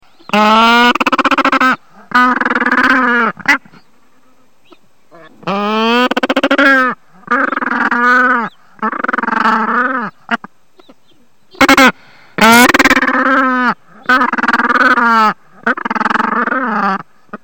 Colonie de gorfous dorés. Anse de la Caverne.